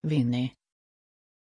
Pronunciation of Winnie
pronunciation-winnie-sv.mp3